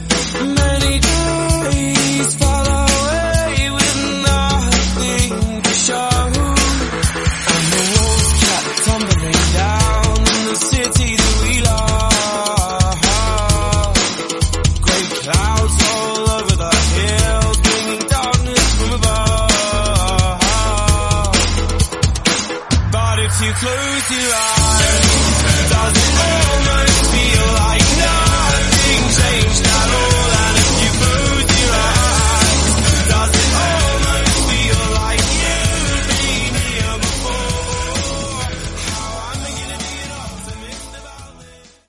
Genres: BOOTLEG , DANCE , HIPHOP
Dirty BPM: 124 Time